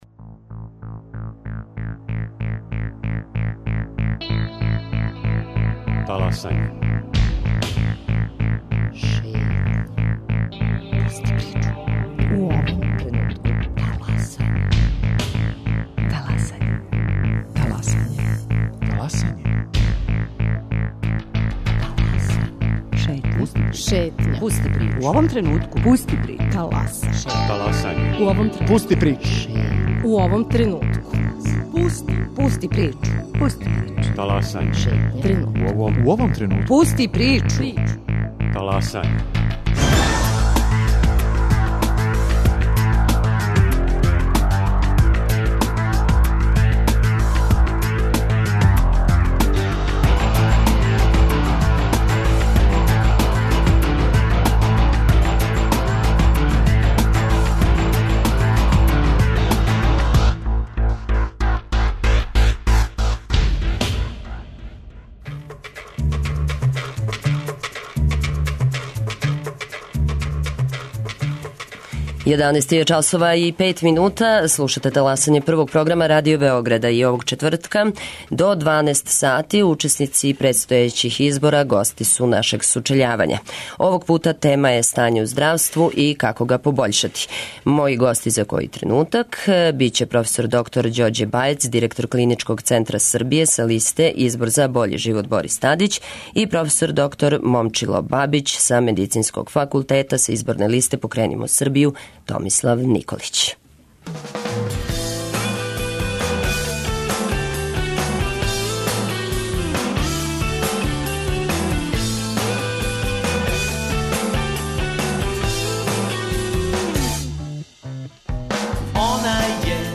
Као и сваког четвртка до 6. маја, у термину од 11 до 12 часова, учесници предстојећих избора учествују у сучељавању. Овог пута тема је здравство.